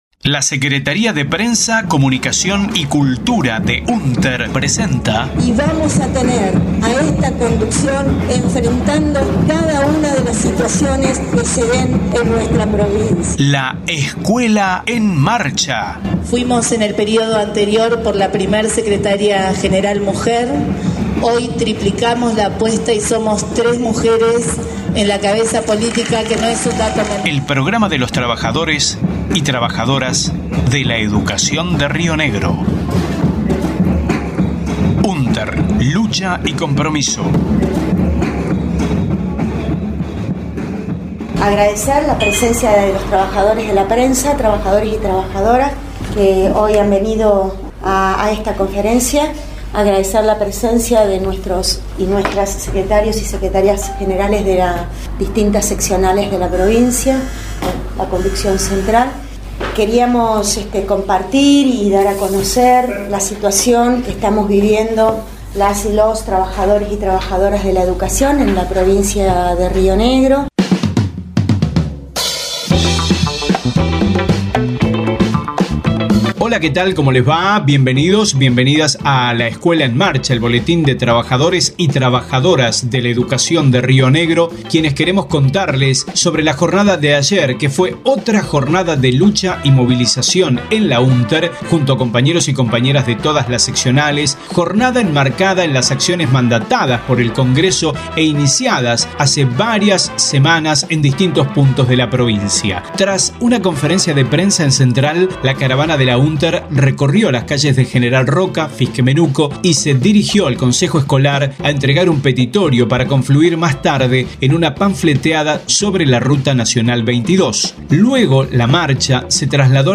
audio de conferencia de prensa